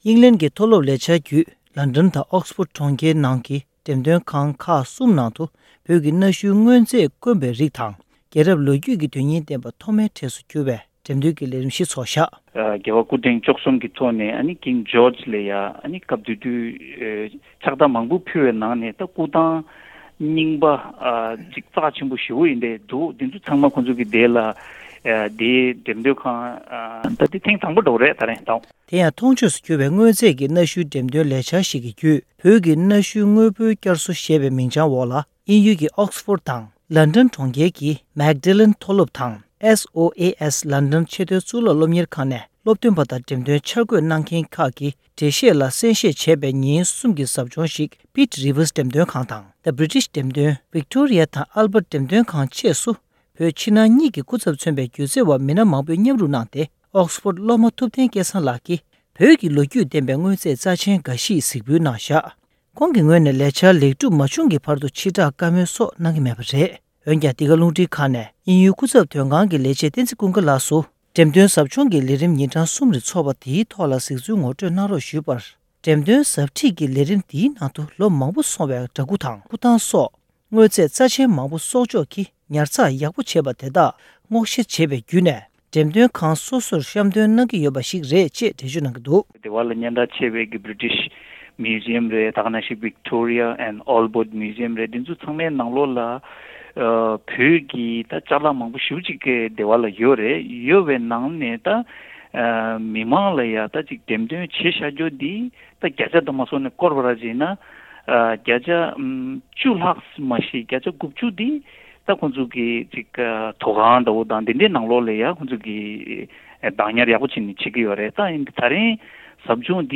ས་གནས་རང་ལ་འབྲེལ་མཐུད་བཅར་འདྲི་གནང་སྟེ་ཕྱོགས་སྒྲིག་ཞུས་པའི་ལེ་ཚན་ཞིག་གསན་རོགས་གནང༌༎